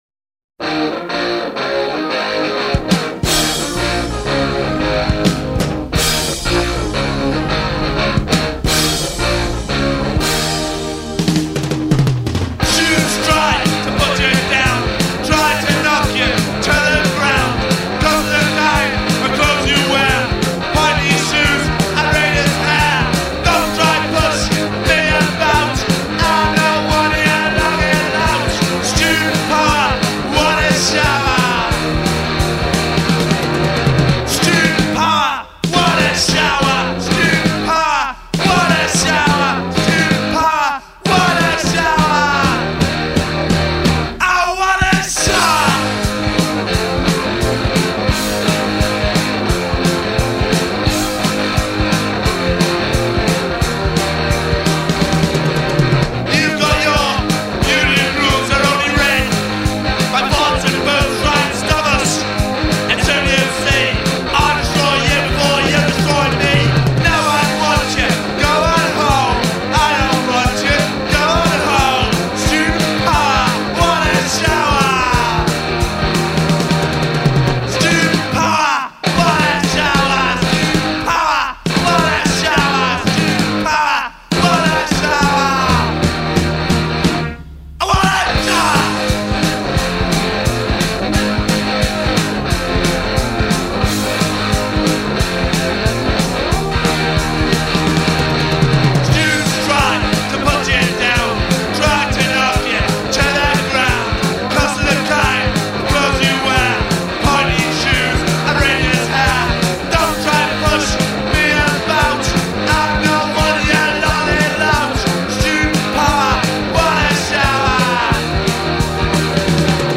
the politically charged side of Punk.
Politically charged. What Punk was all about.